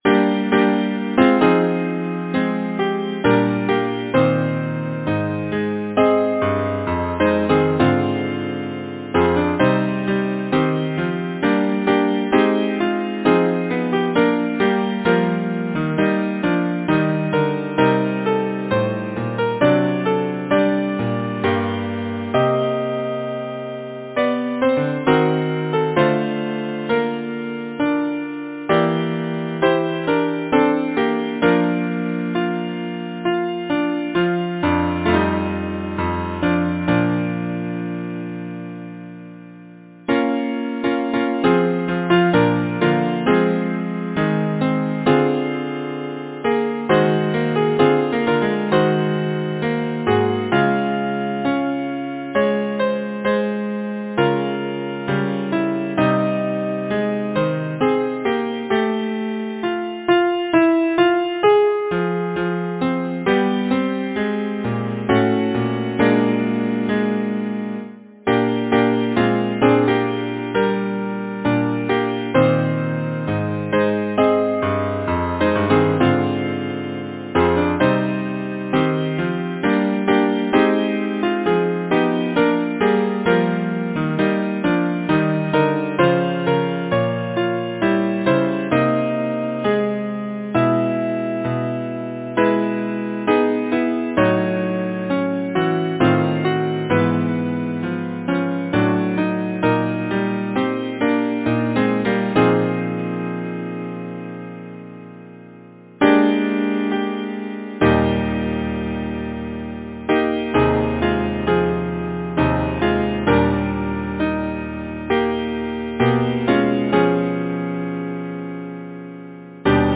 Title: A song at evening Composer: Percy Pitt Lyricist: Sara King Wiley Number of voices: 4vv Voicing: SATB Genre: Secular, Partsong
Language: English Instruments: A cappella